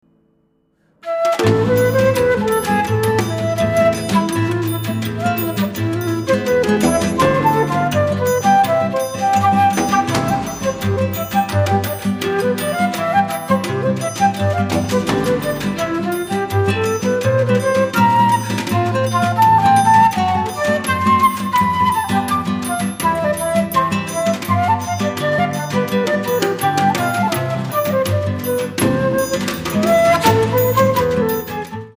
at studio Voice
フルート、ギター、打楽器
２曲がラテンジャズ風のアレンジで演奏される。